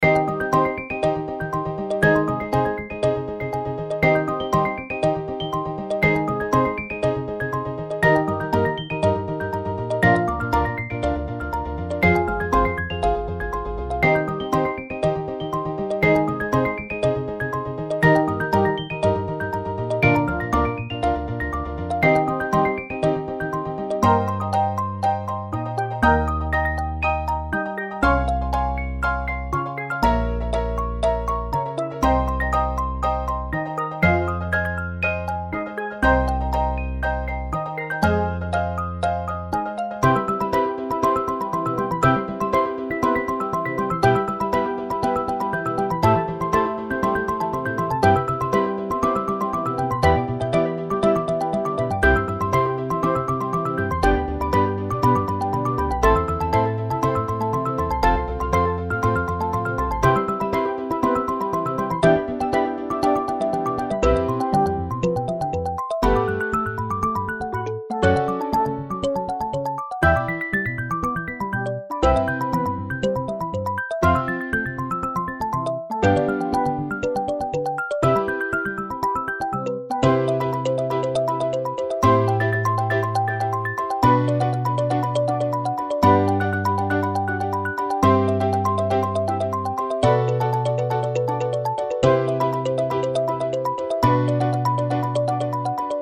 カリンバ、スティールドラム、アコースティックベース、ピアノ